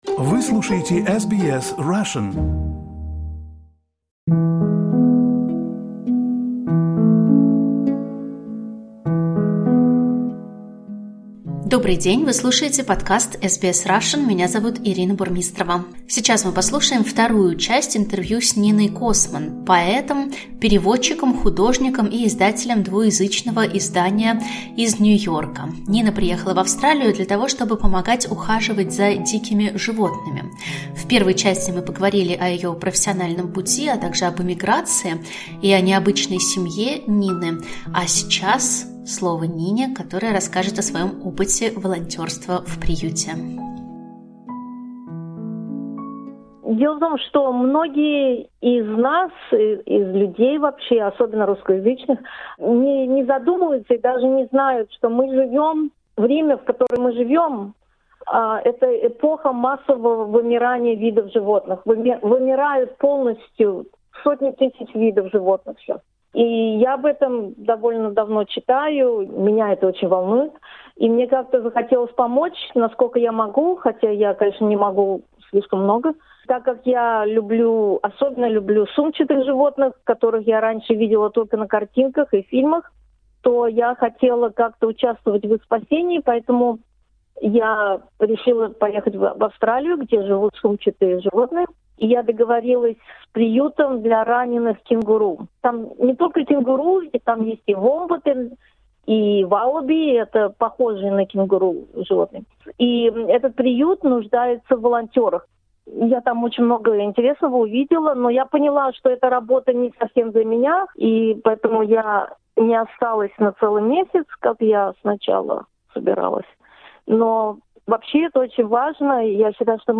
This is the second part of an interview with the poet